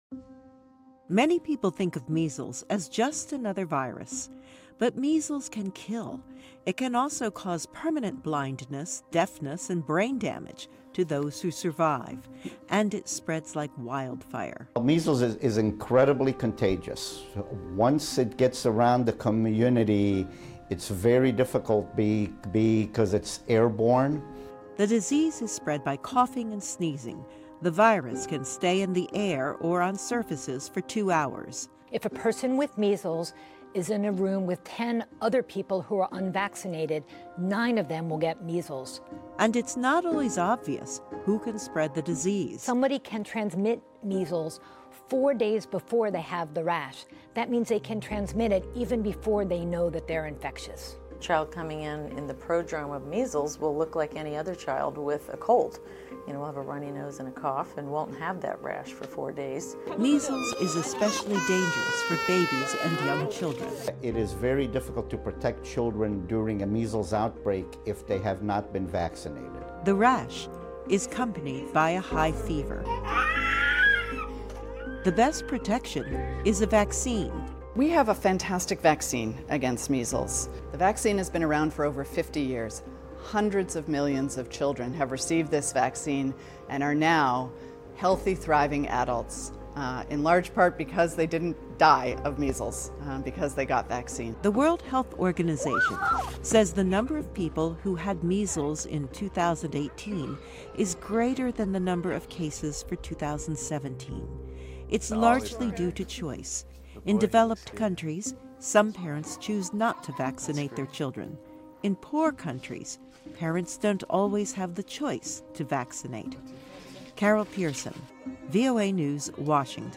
常速英语:麻疹传染性不可小觑